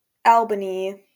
New Zealand.wav Audio pronunciation file from the Lingua Libre project.